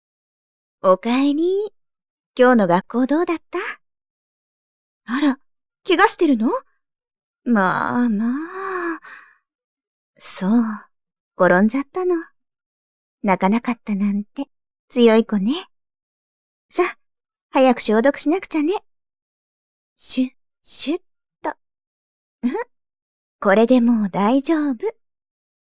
やさしいお母さん